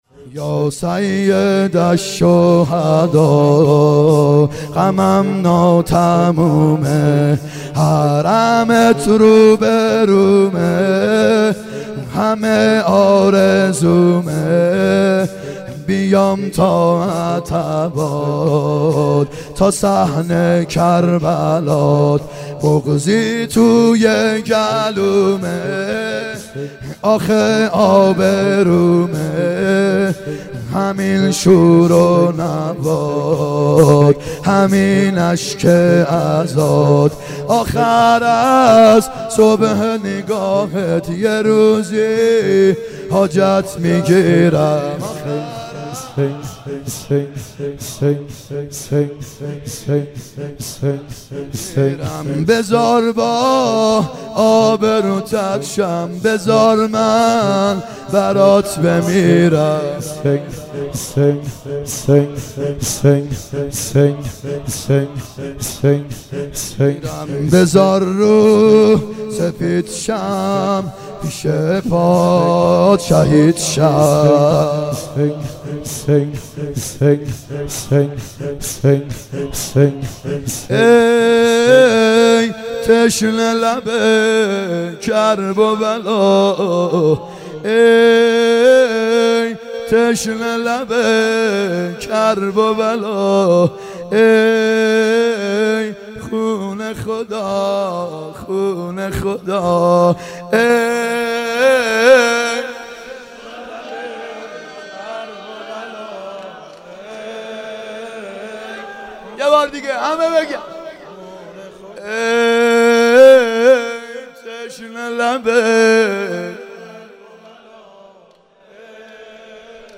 محرم 90 شب یازدهم شور
محرم 90 ( هیأت یامهدی عج)